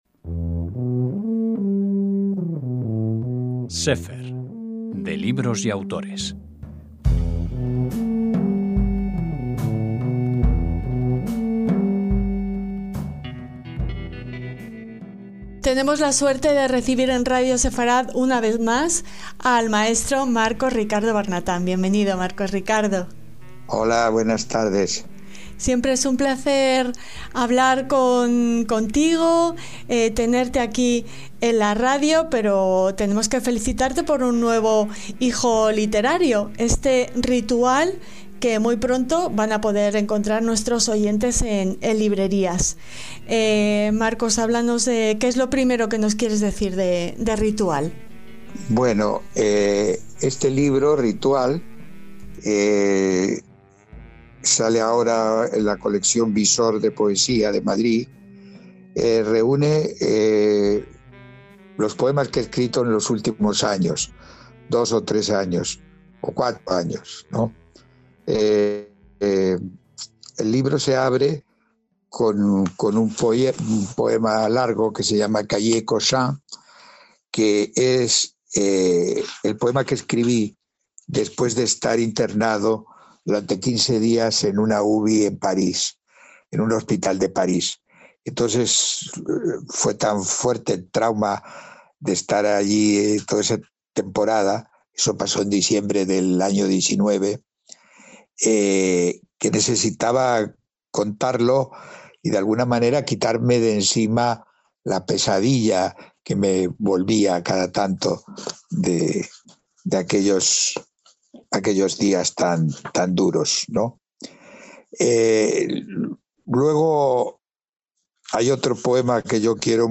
Marcos Ricardo Barnatán nos lee el poema La luna de Nissan.